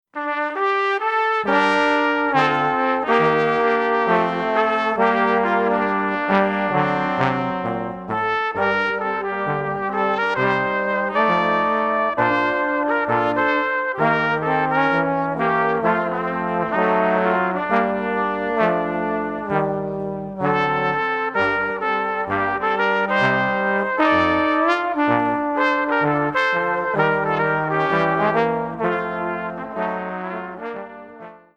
Wonderful settings of Luther songs in the typical swinging